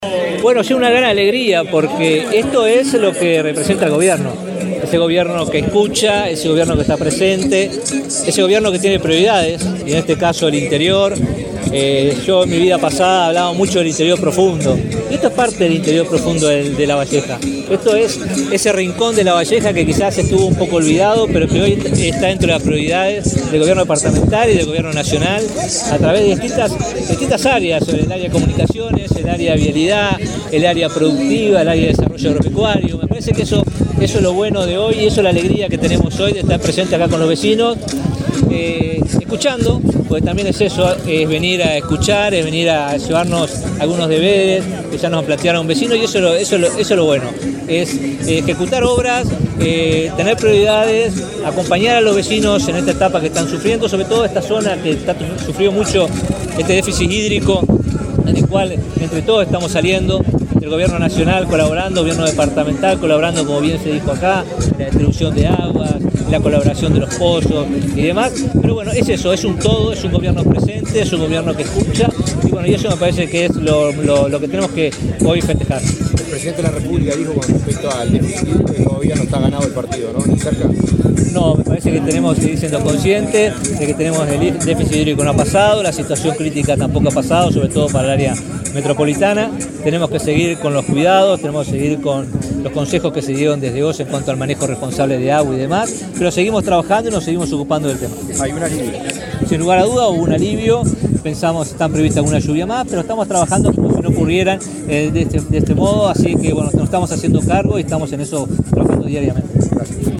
Declaraciones del ministro de Ambiente, Robert Bouvier
El sábado 22, el ministro de Ambiente, Robert Bouvier, participó en la inauguración de obras en Casupá, departamento de Lavalleja.